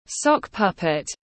Con rối tất tiếng anh gọi là sock puppet, phiên âm tiếng anh đọc là /ˈsɒk ˌpʌp.ɪt/